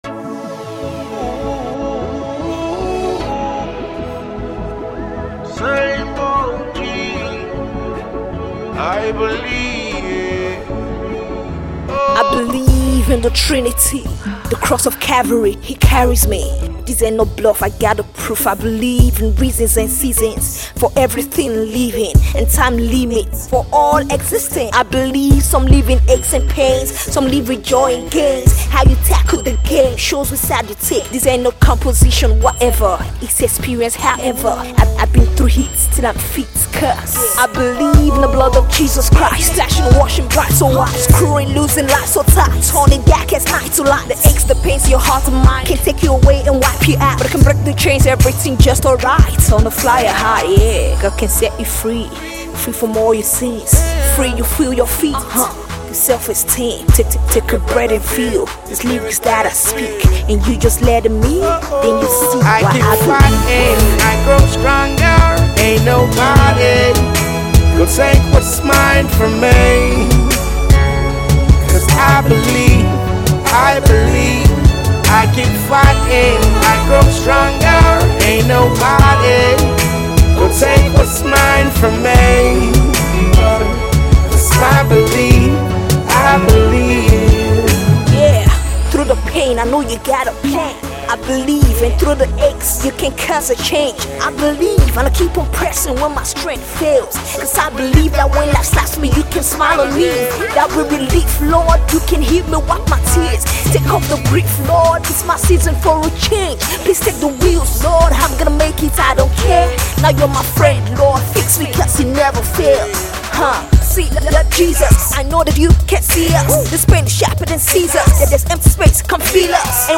Gospel, Rap
brand new ballad